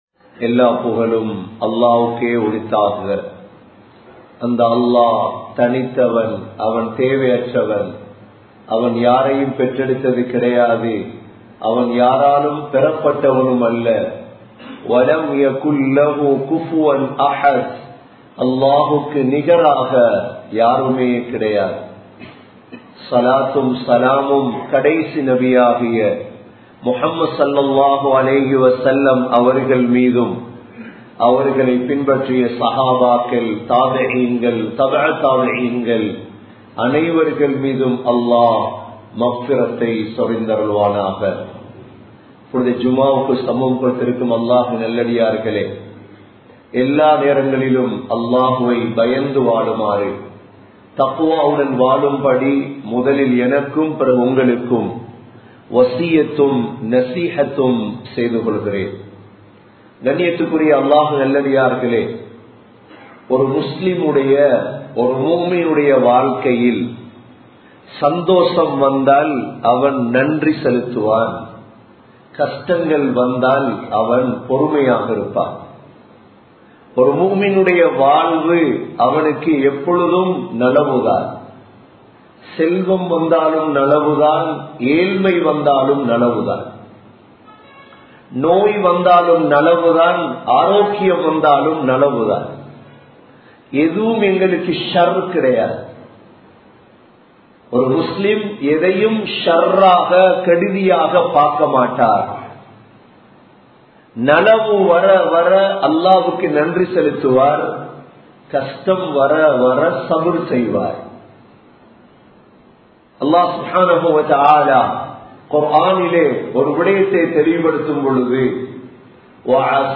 Islam Koorum Suhathaaram (இஸ்லாம் கூறும் சுகாதாரம்) | Audio Bayans | All Ceylon Muslim Youth Community | Addalaichenai